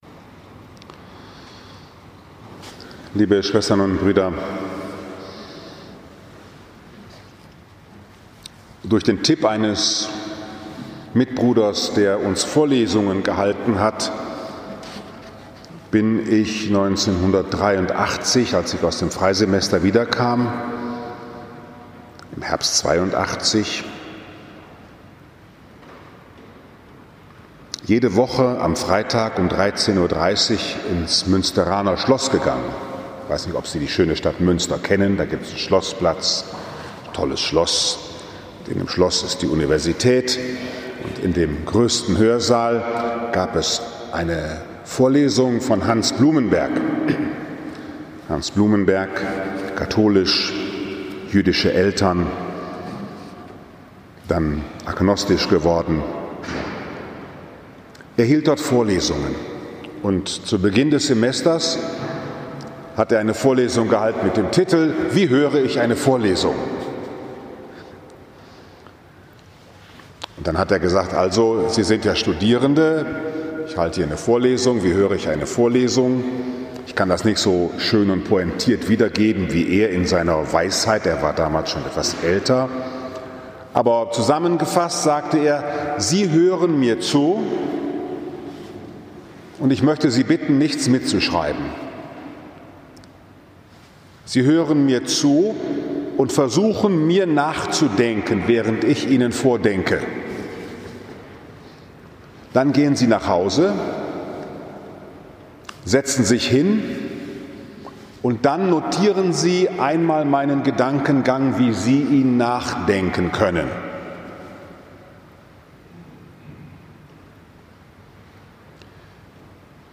Nachfolge als Grundexistenzial des Menschen und der Glaube an Christus, der auch uns nachgeht, wenn wir aus stolz nicht mehr nachgehen wollen 24. Januar 2021, 11 Uhr, Liebfrauenkirche Frankfurt am Main, 3. So.i.J. B